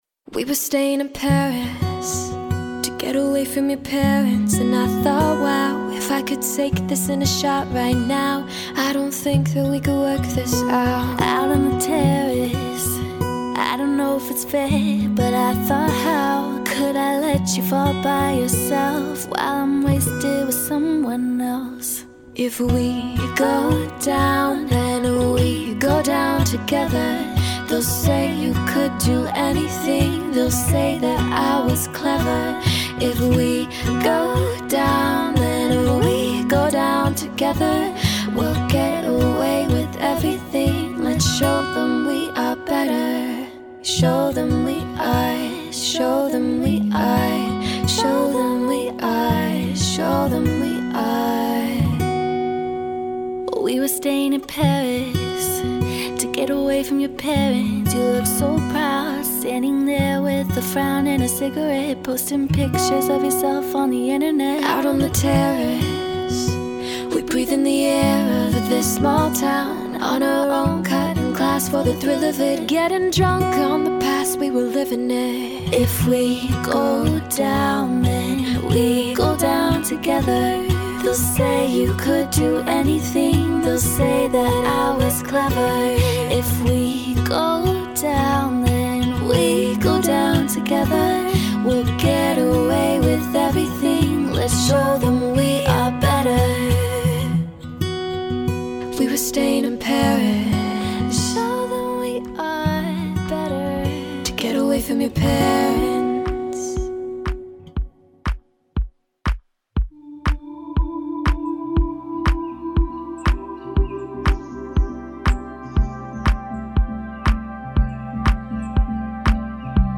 Un miniMix So Sweet à emporter pour se souvenir de l’été.